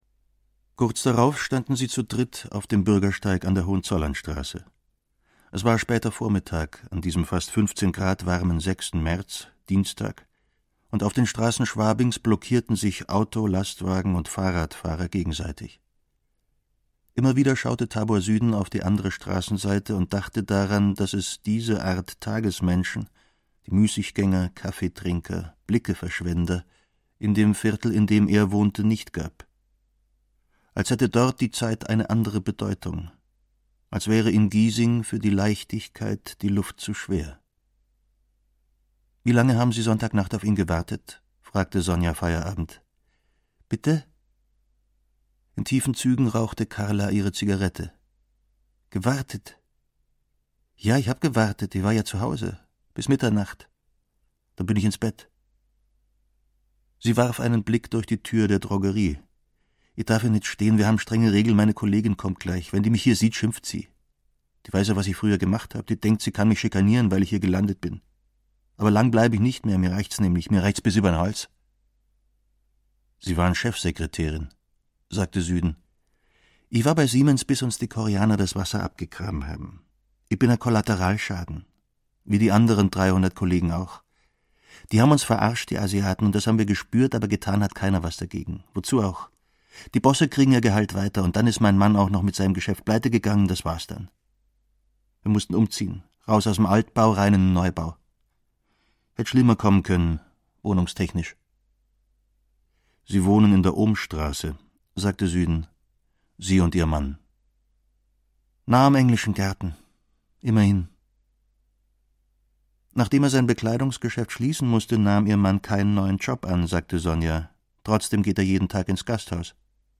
Krimi to go: Der verschwundene Gast - Friedrich Ani - Hörbuch